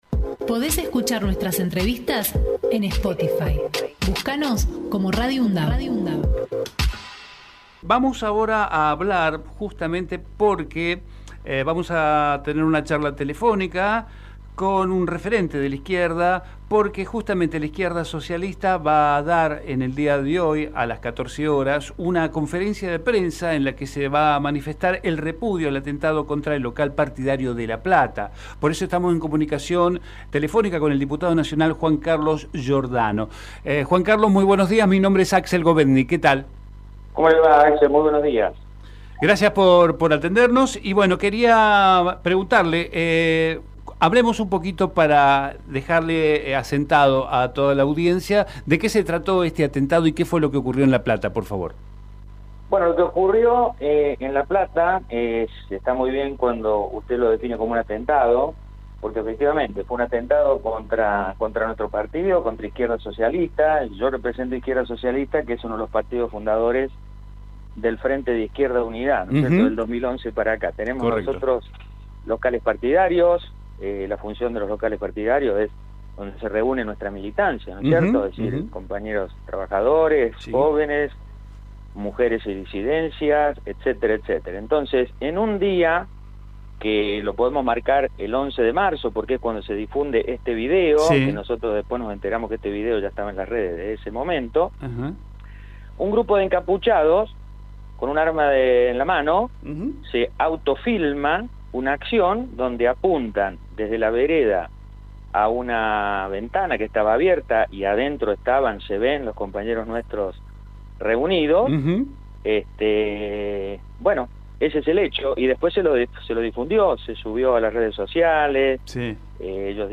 Juan Carlos Giordano en Hacemos PyE Texto de la nota: Compartimos la entrevista realizada en Hacemos PyE con Juan Carlos Giordano, Diputado Nacional y dirigente de Izquierda Socialista. Conversamos sobre el atentado contra el local partidario en La Plata.